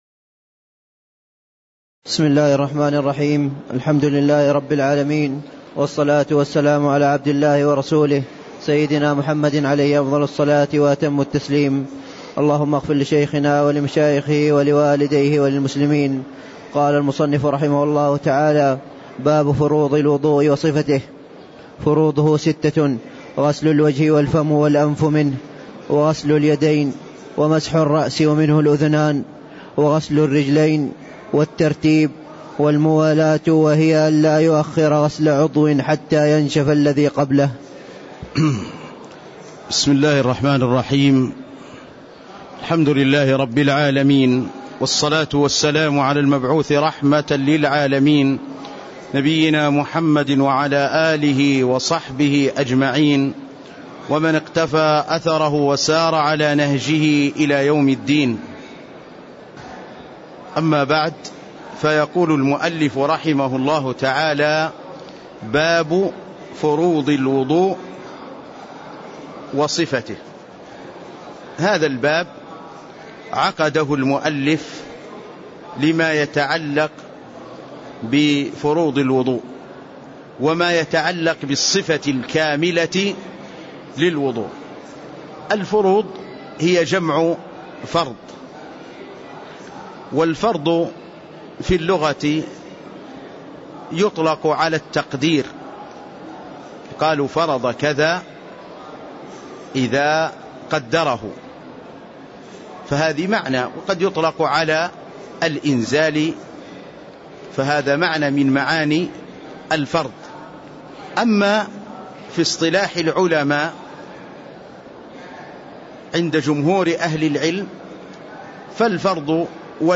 تاريخ النشر ٨ جمادى الأولى ١٤٣٥ هـ المكان: المسجد النبوي الشيخ